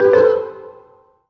happy_collect_item_11.wav